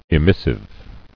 [e·mis·sive]